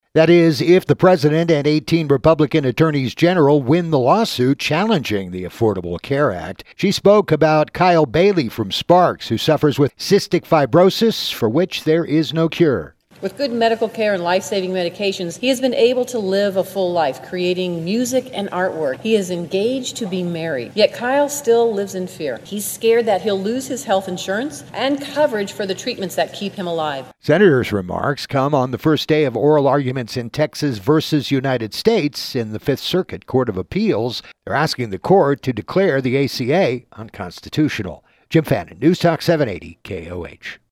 Senator Catherine Cortez Masto took to the senate floor, to urge members to end their silence, and stand up for the Affordable Care Act.